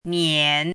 chinese-voice - 汉字语音库
nian3.mp3